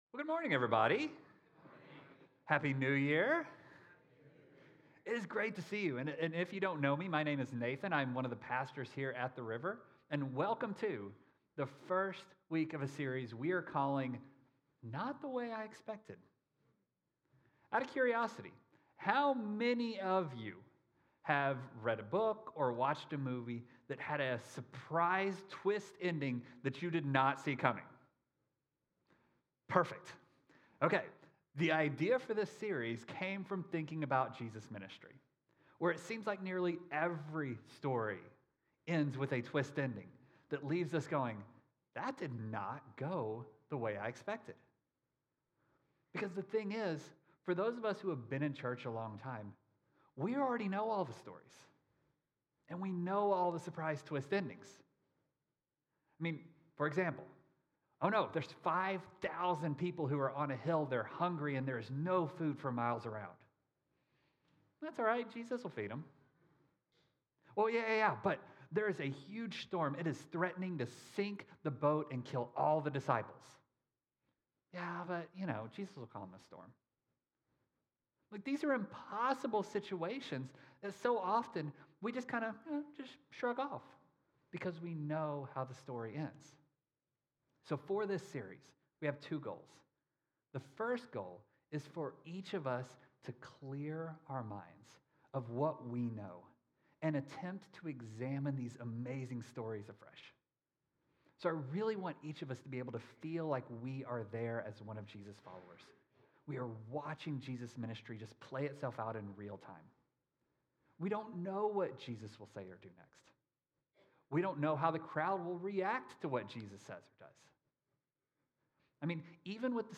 Sermons Not The Messiah I Expected